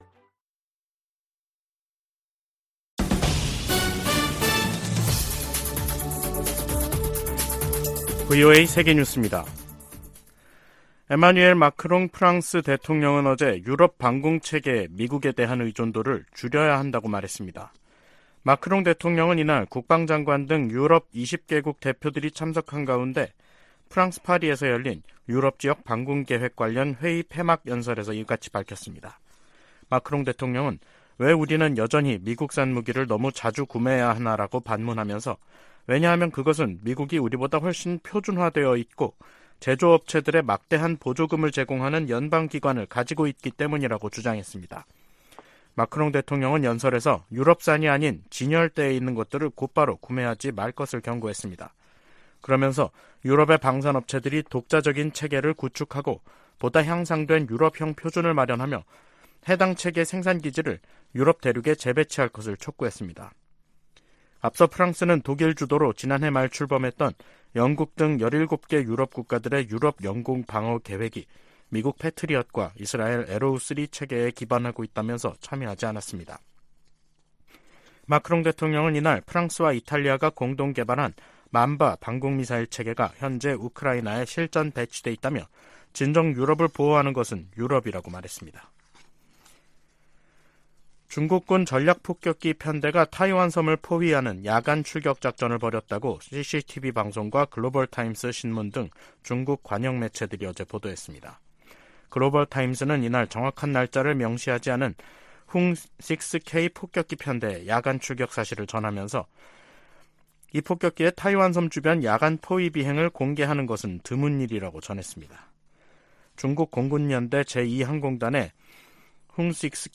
VOA 한국어 간판 뉴스 프로그램 '뉴스 투데이', 2023년 6월 20일 2부 방송입니다. 베이징을 방문한 블링컨 미 국무장관은 중국에 북한이 도발을 멈추고 대화 테이블로 나오도록 영향력을 행사해 줄 것을 촉구했습니다. 미 국방부 콜린 칼 차관의 최근 일본 방문은 미일 동맹의 진전을 보여주는 것이었다고 국방부가 평가했습니다. 한국군은 대북 무인기 작전 등을 주요 임무로 하는 드론작전사령부를 9월 창설할 예정입니다.